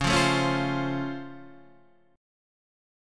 C#+M7 chord